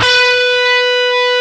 DIST GT1-B3.wav